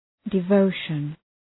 Shkrimi fonetik {dı’vəʋʃən}
devotion.mp3